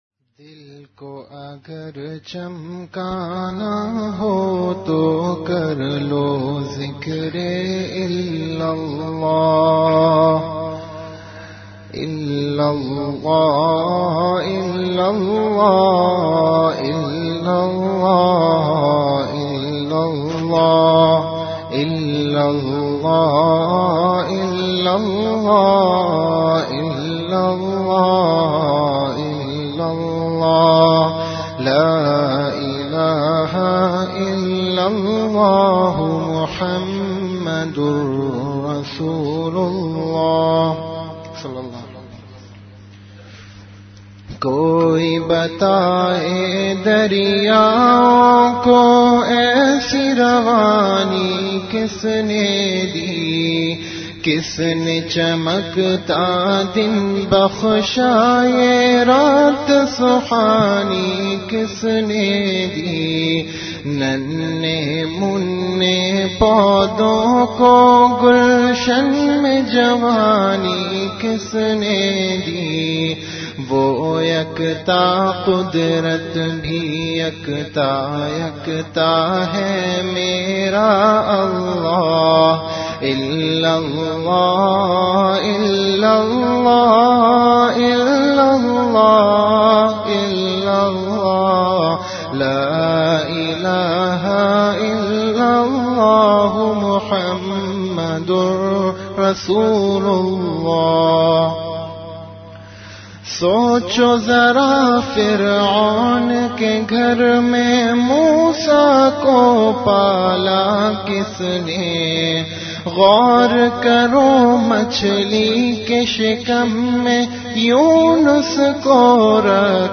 Majlis-e-Zikr